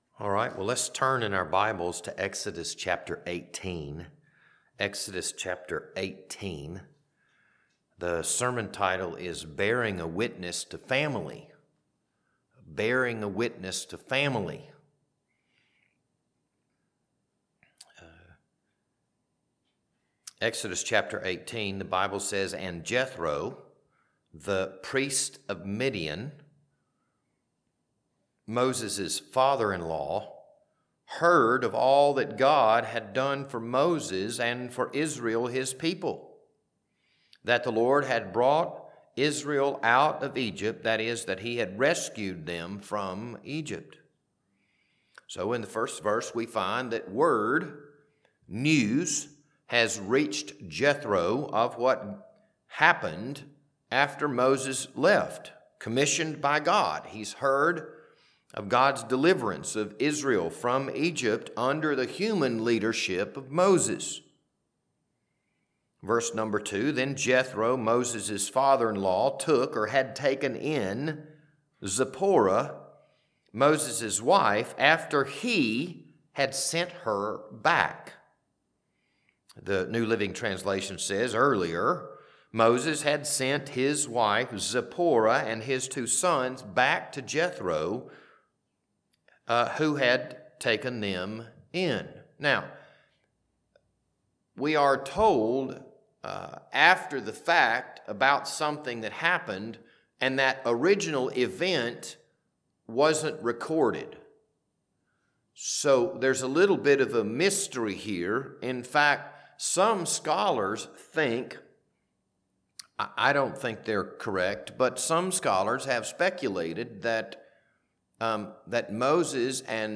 This Wednesday evening Bible study was recorded on April 15th, 2026.